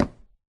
wood4.ogg